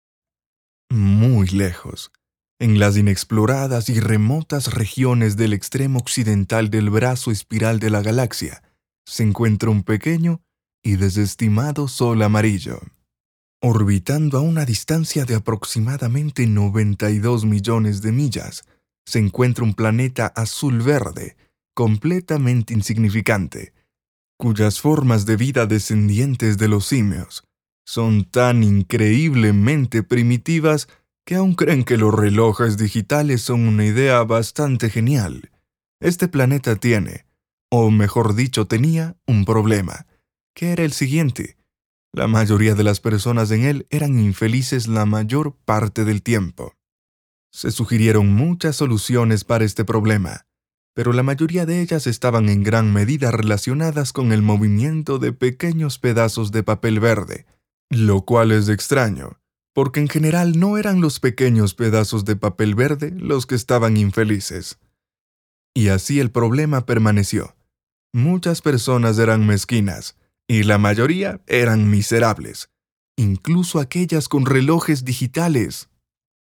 Échantillons de voix natifs
Livres audio